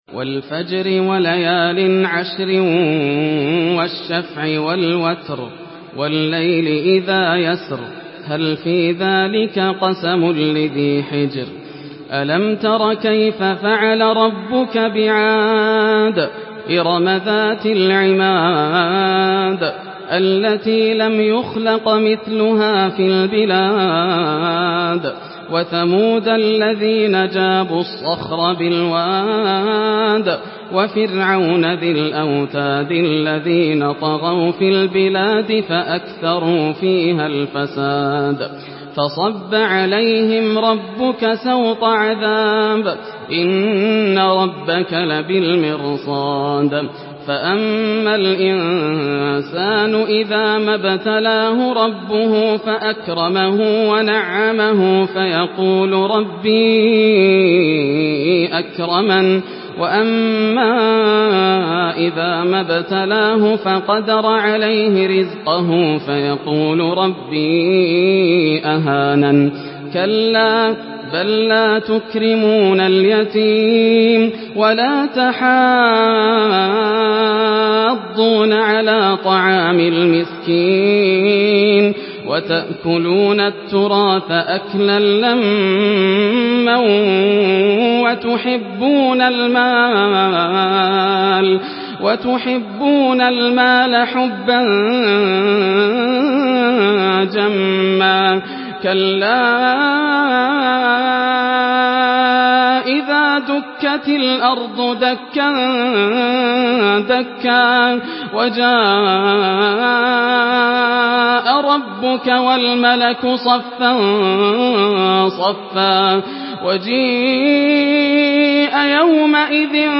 سورة الفجر MP3 بصوت ياسر الدوسري برواية حفص
مرتل